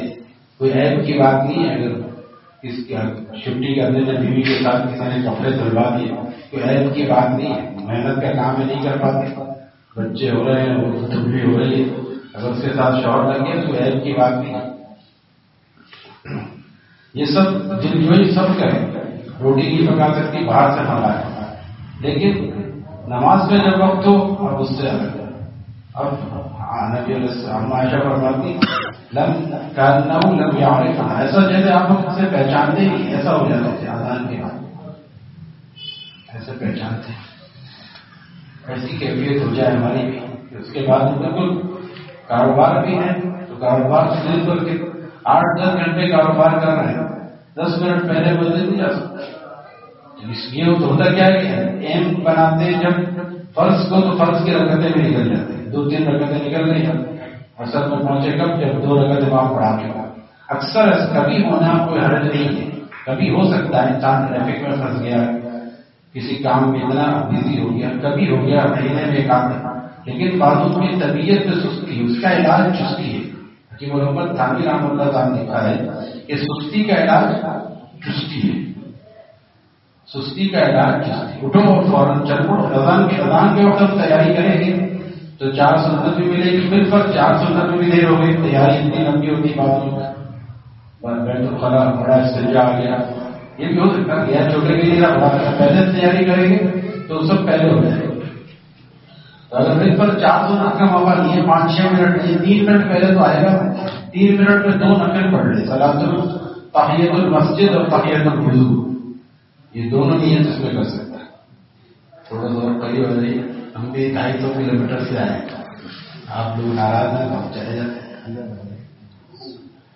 Bayan at Bab e Jannat Masjid, Nawabshah